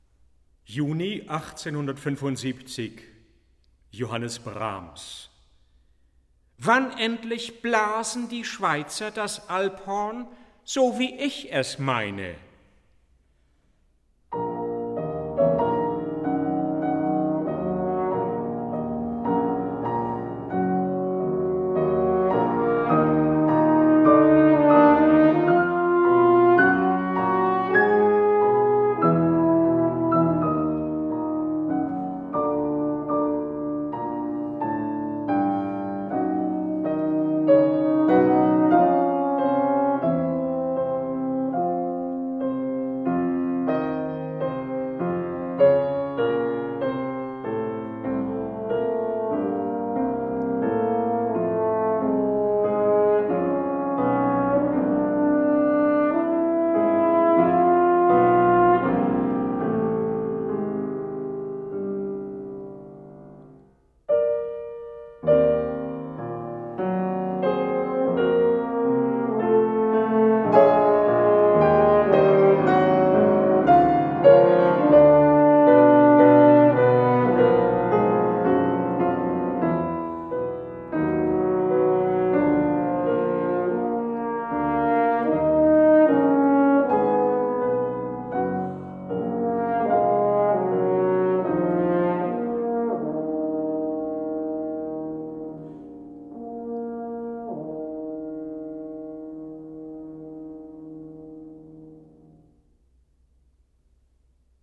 Albert Moeschinger: Johannes brahms, 1875 (french horn, piano)